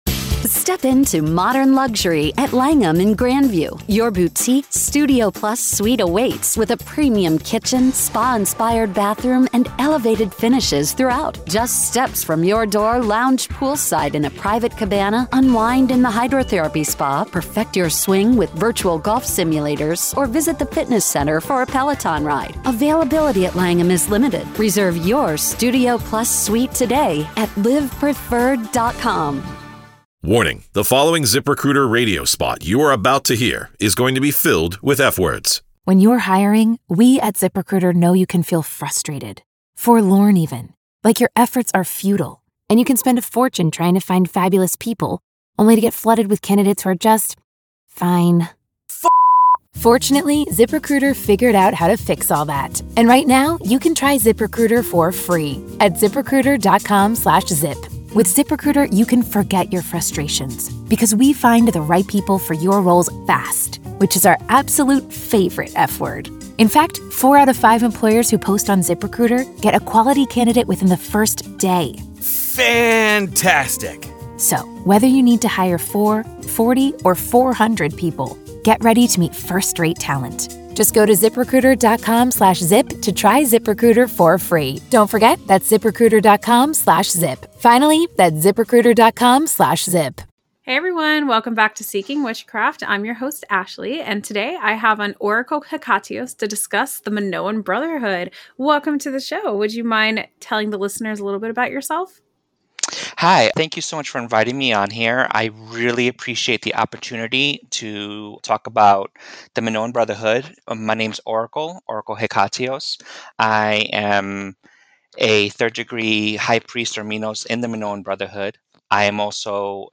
Together, they explore the origins of the Brotherhood, its values, and how it fits within Paganism and witchcraft. Whether you’re curious about queer magical traditions or looking to deepen your understanding of inclusive paths in modern spirituality, this conversation offers powerful insight and perspective.